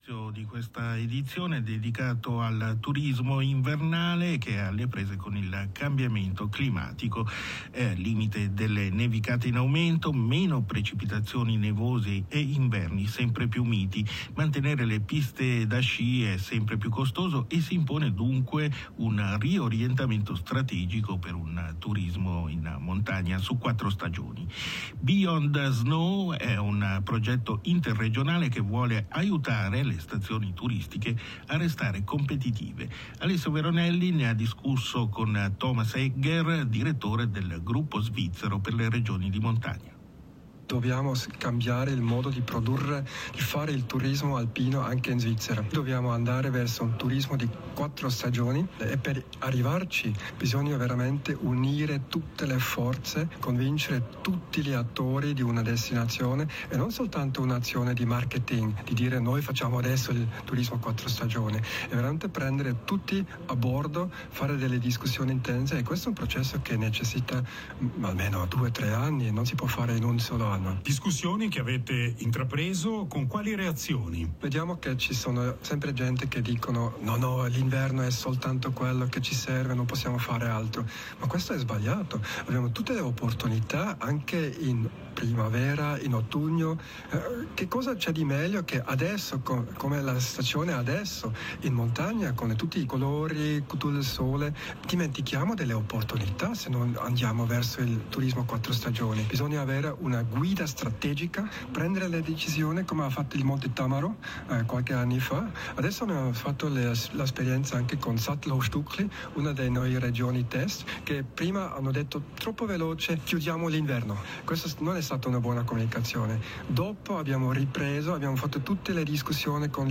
Interview-Beyond-Snow-RSI.m4a